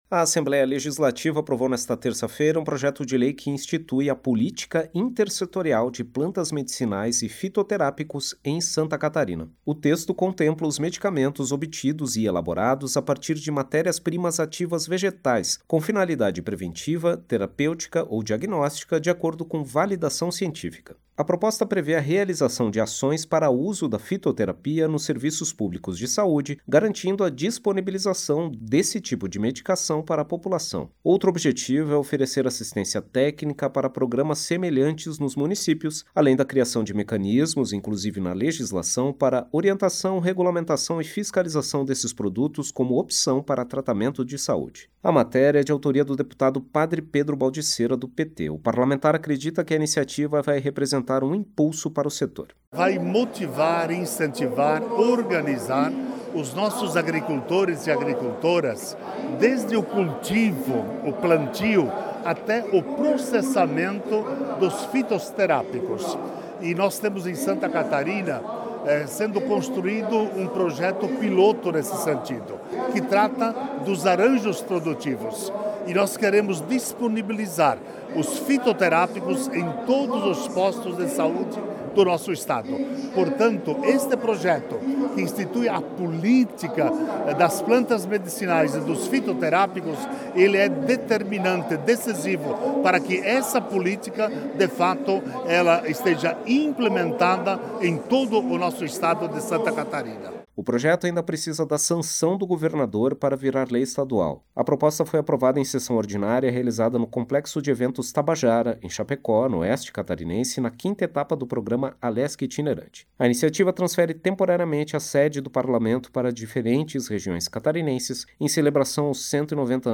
Entrevista com: